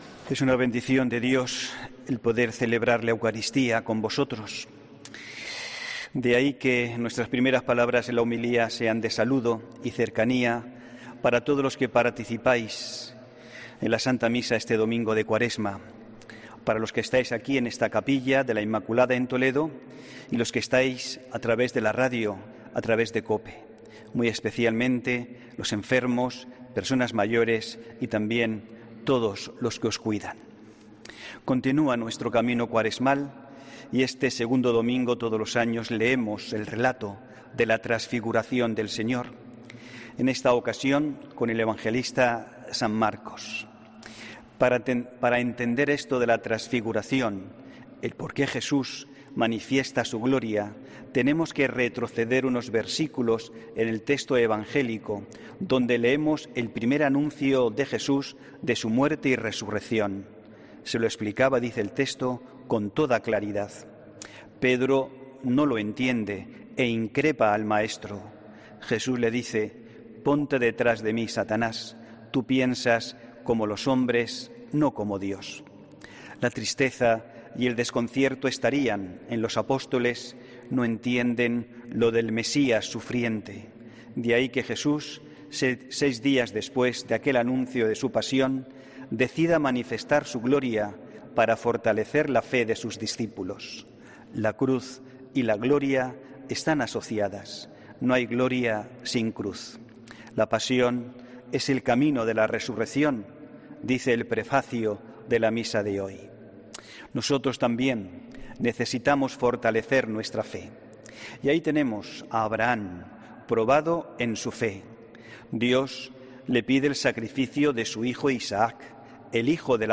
HOMILÍA 28 FEBRERO 2021